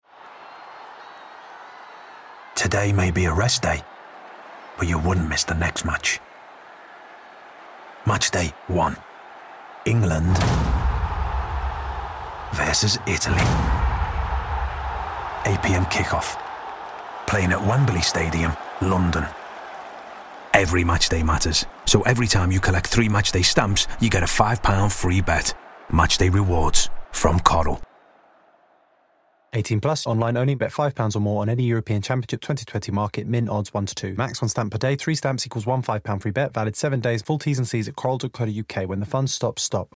Coral worked with A Million Ads last year to promote its betting service for the Euro 2020 fixtures. Our solution was a dynamic audio ad with thousands of variations, using a live API integration to call out the tournament round, teams playing, kick off time and stadium as they happened.